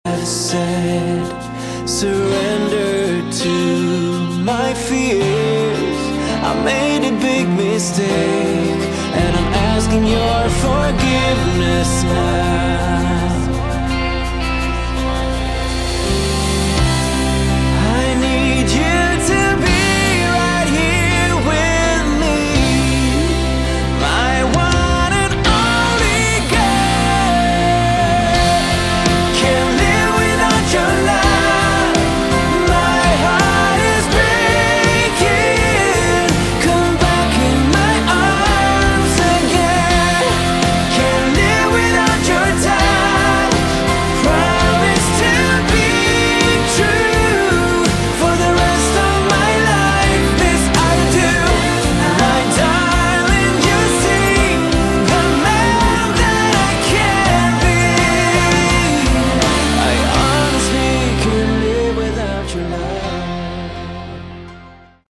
Category: Melodic Rock
lead & backing vocals
guitars
piano & keyboards
bass
drums
Uplifting and extremely melodic as you'd expect.